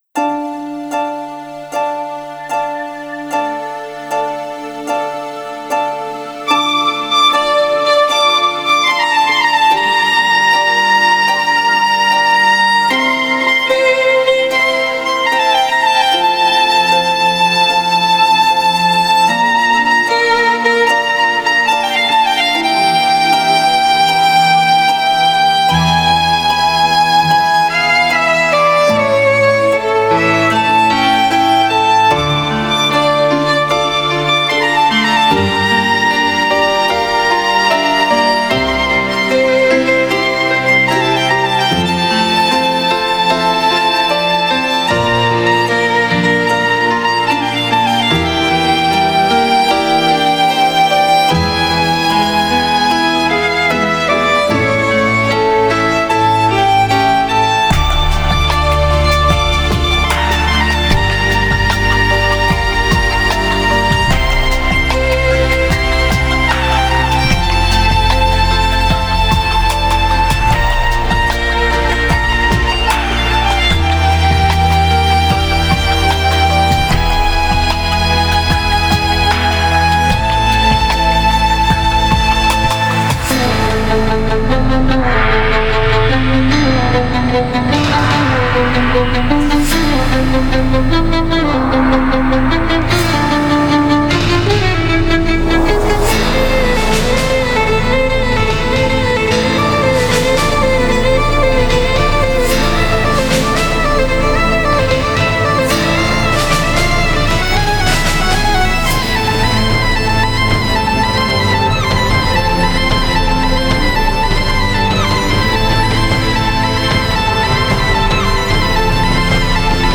نوع آهنگ: لایت]